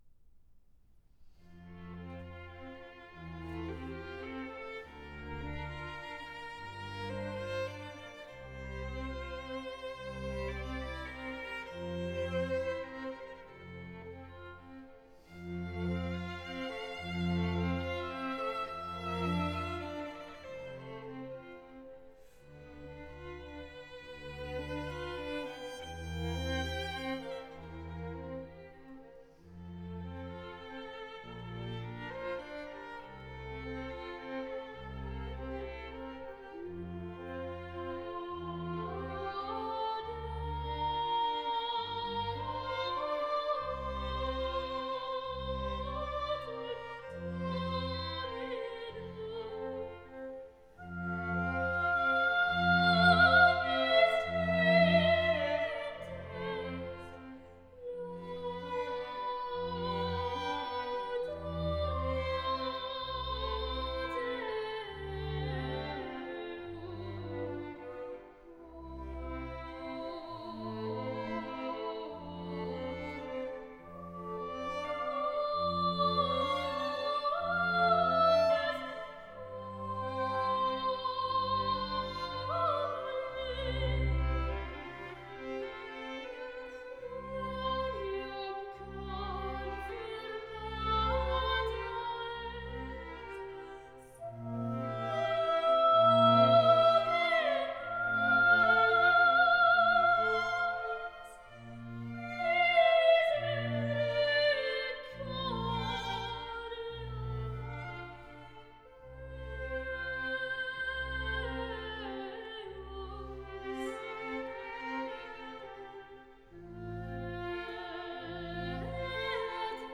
Répétition de la pièce musicale N° 734b
Répétition SATB par voix